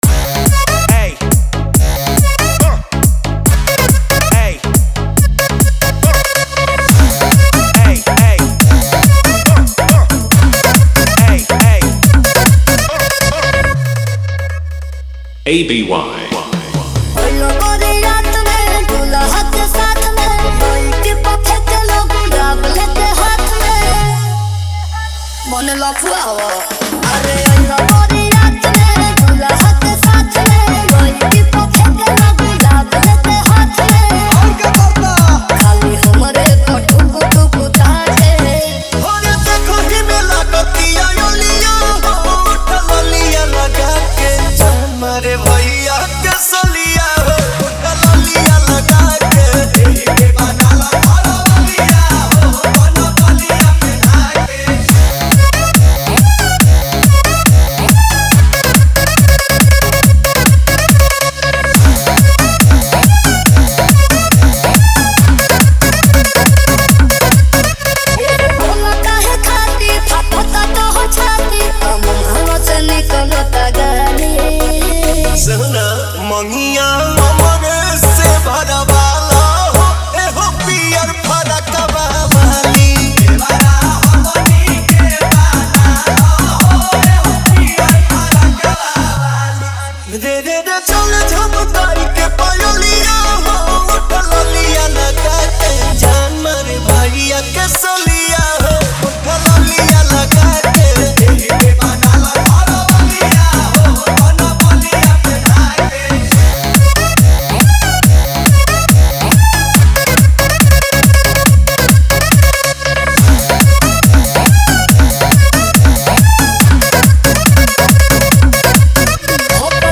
DJ Dialogue Intro – U.P.70 Feel Edition
[Start with sad flute loop + heart beat FX]
[Drop FX: sad vocal slice + 808 roll + reverb]